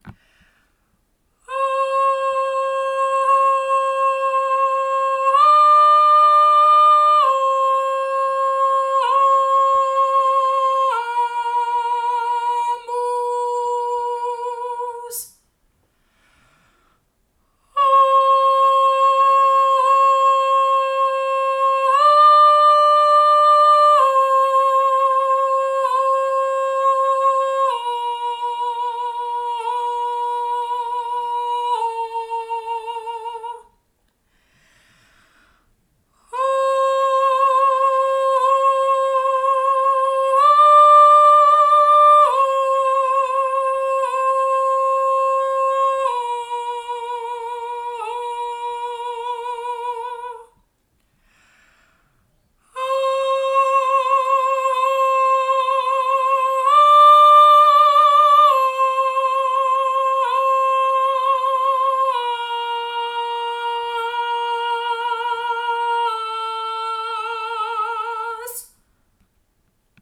Très ancien chant religieux à 3 voix
46f07-benedicamus-voix-aigue.mp3